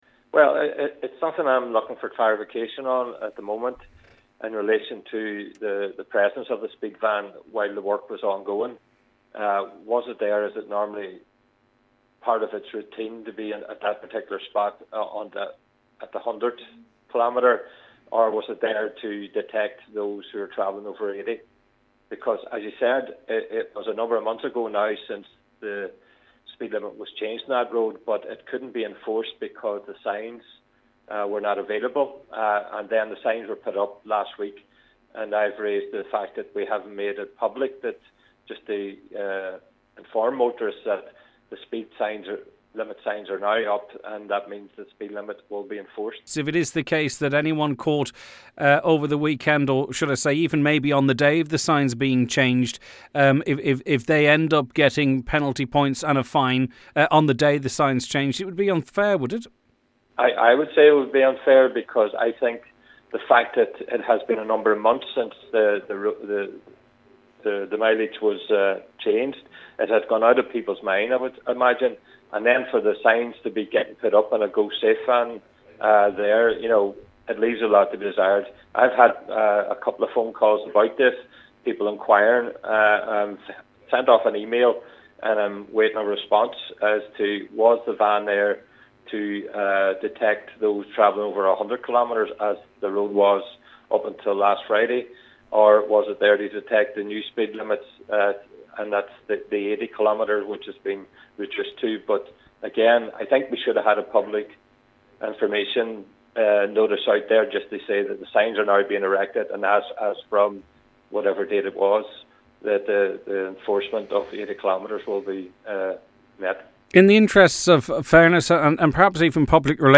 Councillor Gerry McMonagle is seeking clarification on which speed limit the van was checking, he says if it was 80 kmp/h then those caught as the signs were changing should have the offence waved: